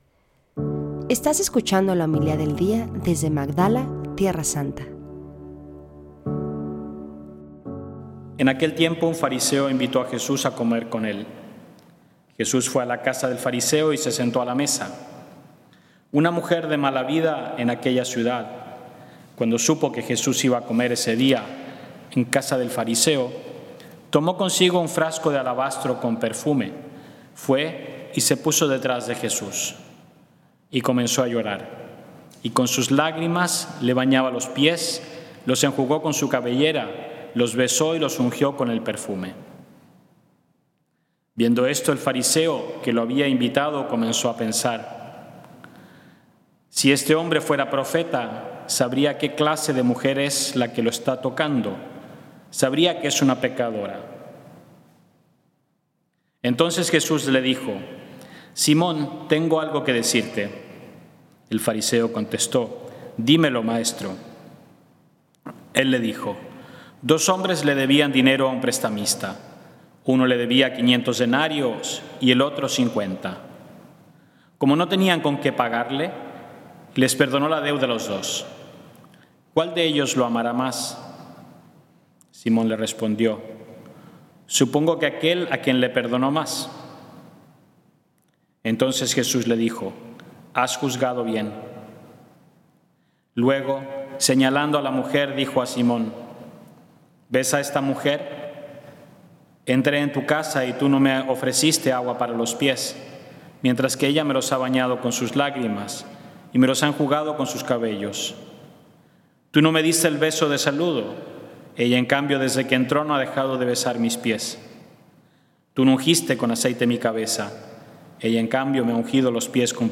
20 de septiembre:“ Lo acompañaban los Doce y algunas mujeres ” – Homilía del día desde Magdala, Tierra Santa – Podcast